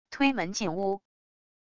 推门进屋wav音频